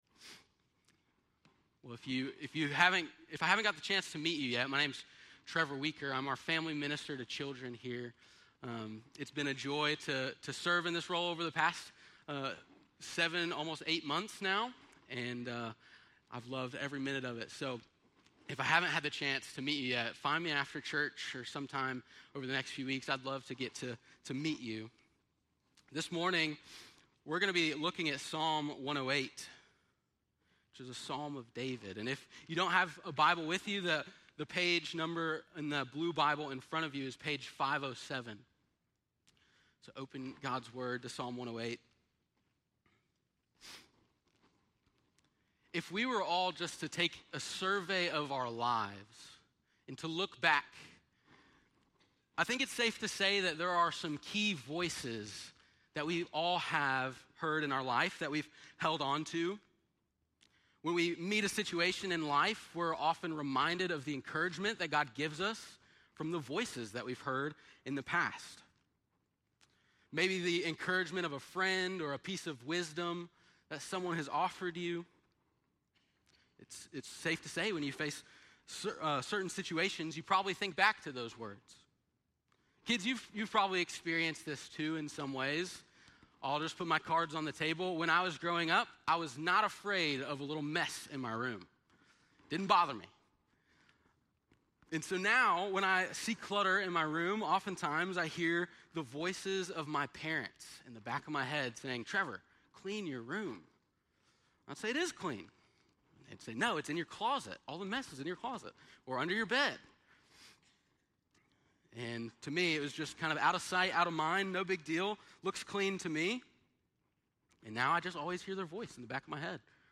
7.28-sermon.mp3